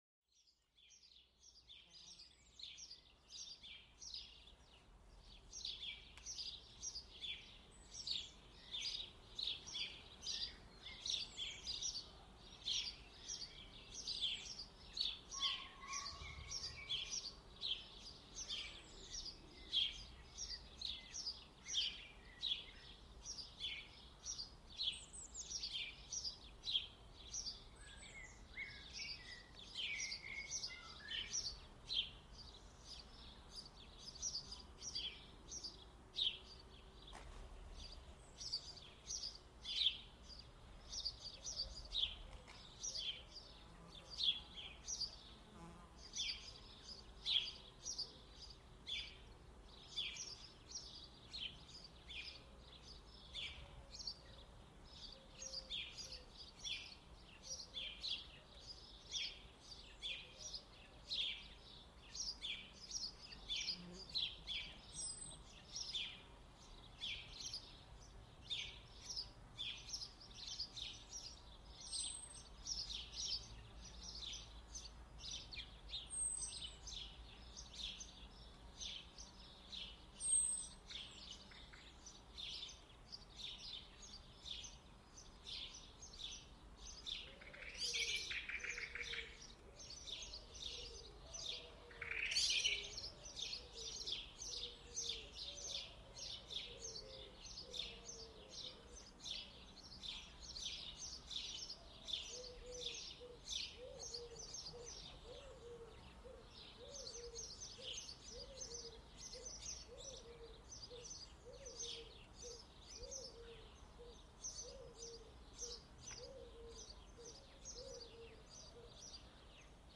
Le chant des oiseaux pour apaiser l'esprit et calmer les tensions
Laissez-vous bercer par le doux chant des oiseaux, un son naturel qui aide à calmer l'esprit. Chaque trille et chaque gazouillis apporte une sensation de paix, vous reconnectant à la nature.